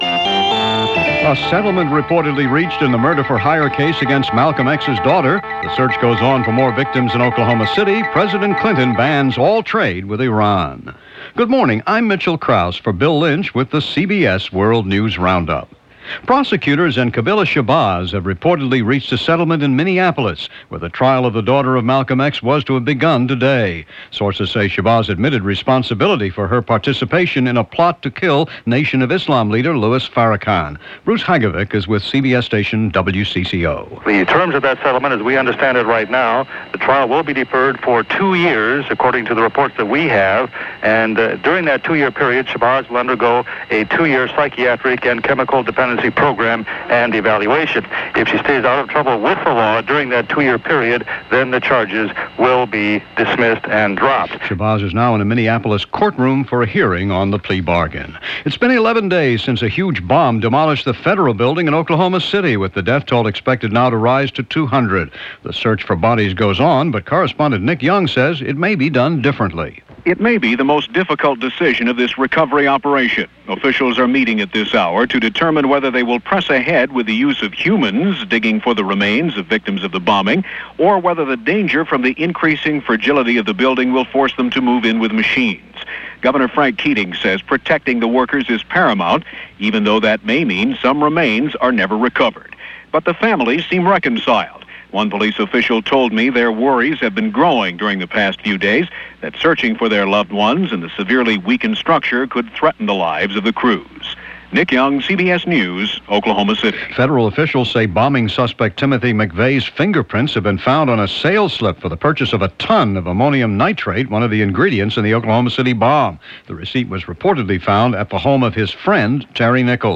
And that’s a small sample of the news this day, which also covered the seemingly never-ending O.J. Simpson Trial and the Oklahoma City Bombing investigation which was busy uncovering more evidence, all from The CBS World News Roundup.